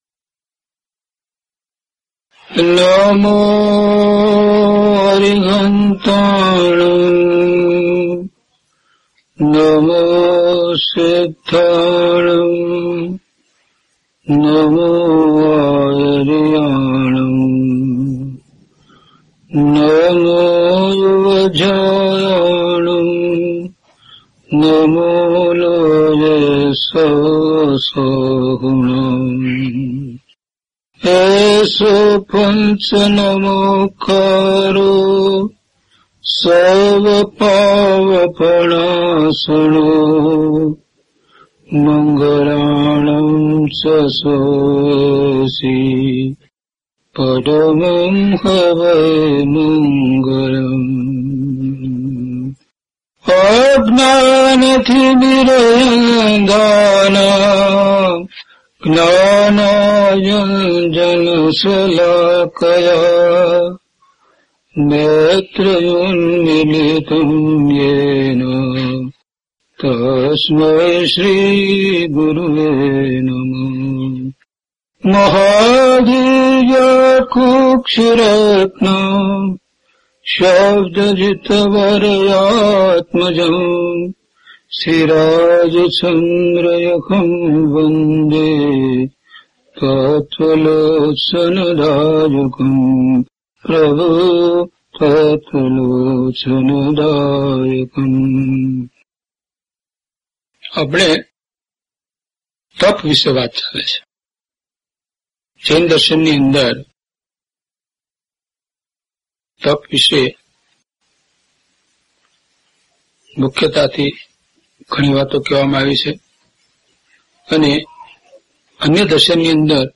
Audio title: DHP043 Tap Ane Dhyan - Pravachan.mp3
DHP043 Tap Ane Dhyan - Pravachan.mp3